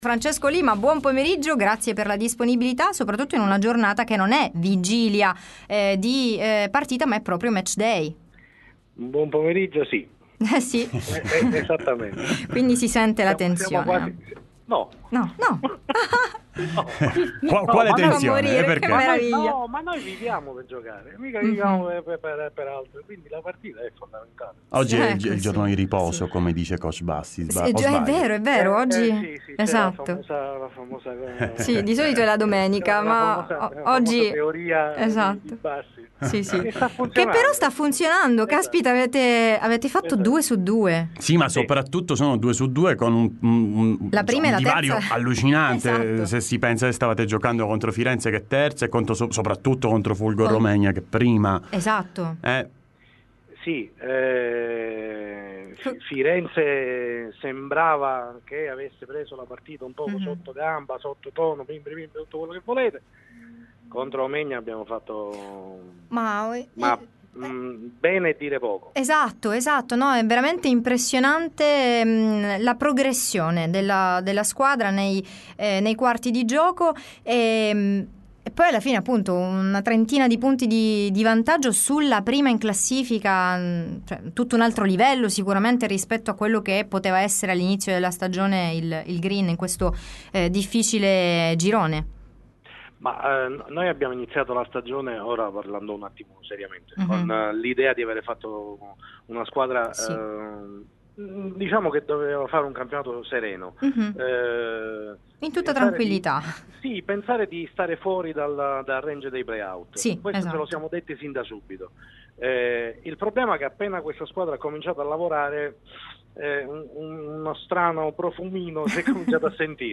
Time Sport Intervista